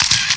camera04.mp3